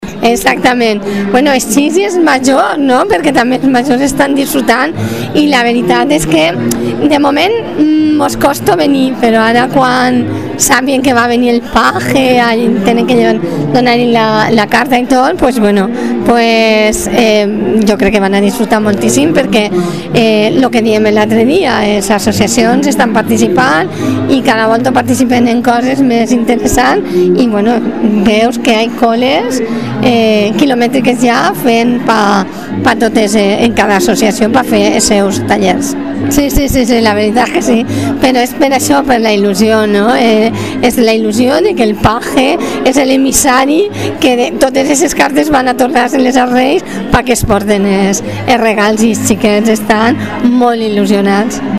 Elisa Santiago, edil de Fiestas